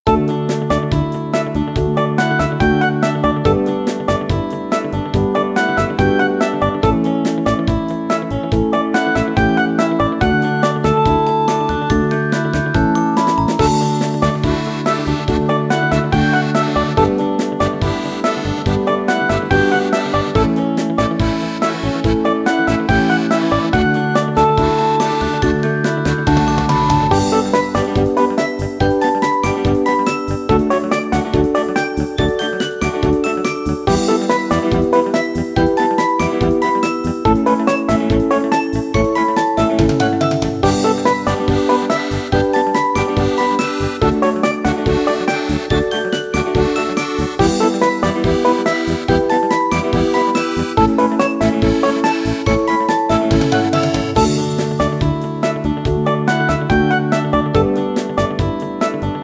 イメージ：明るい ワクワク   カテゴリ：RPG−街・村・日常